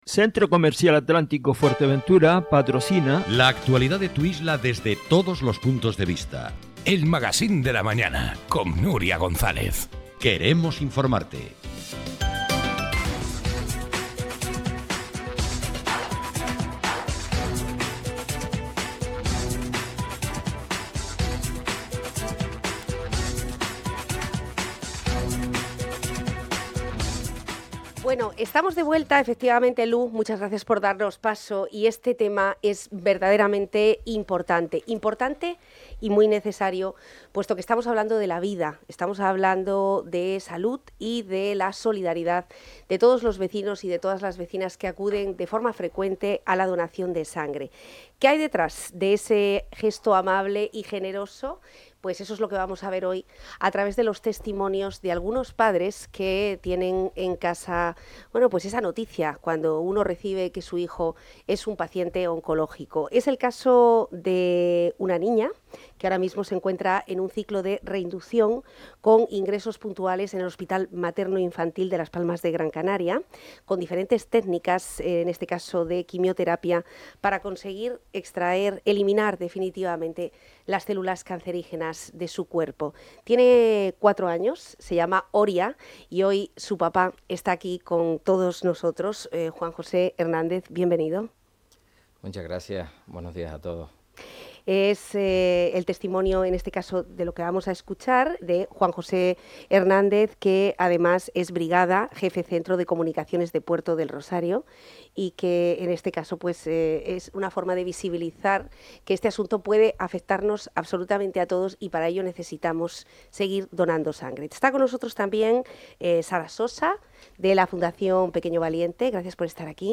Un gesto solidario que salva vidas y la entrevista se puede escuchar aquí: